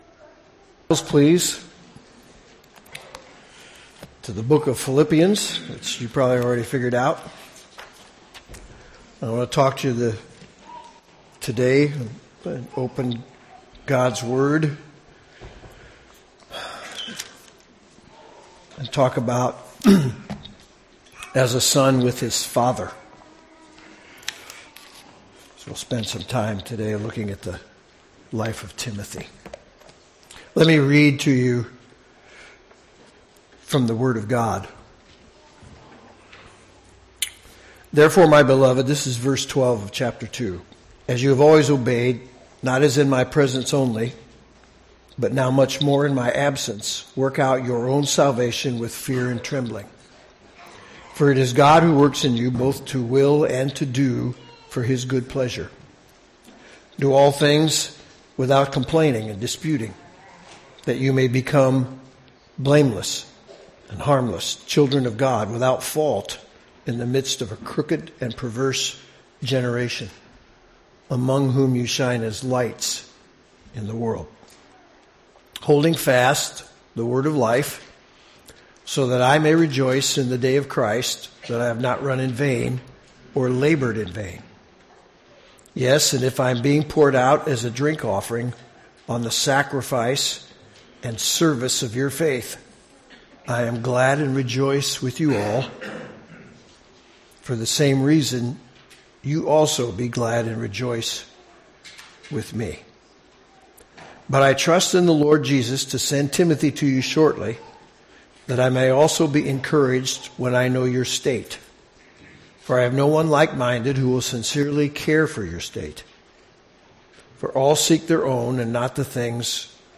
Lakeview Community Church - Hamilton Indiana